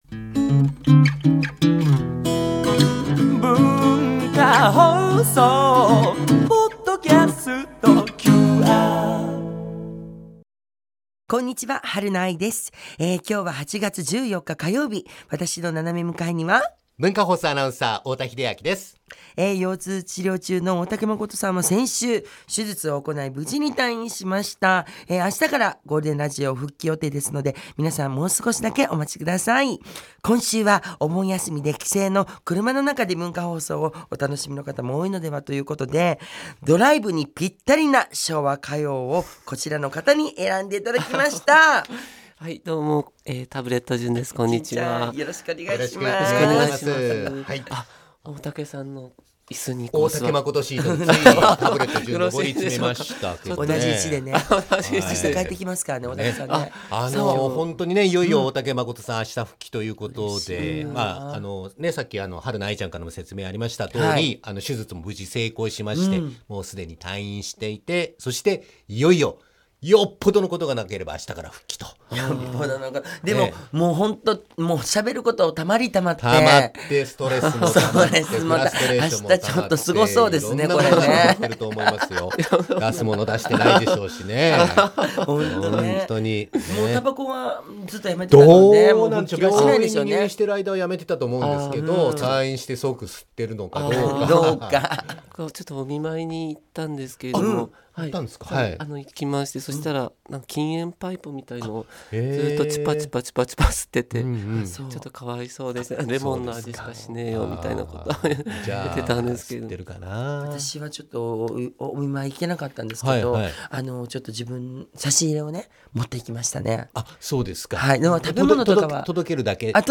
大竹まことさんとパートナーとのオープニングトークをお楽しみください！！